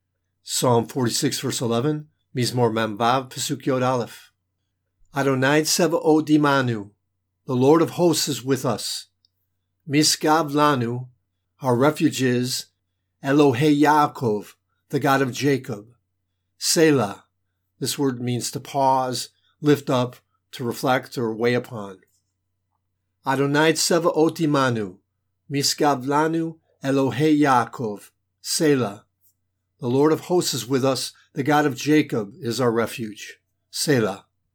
Hebrew Lesson
Psalm 46:11 Hebrew reading: